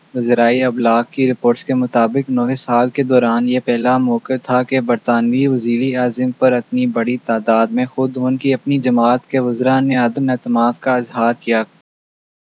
deepfake_detection_dataset_urdu / Spoofed_TTS /Speaker_02 /259.wav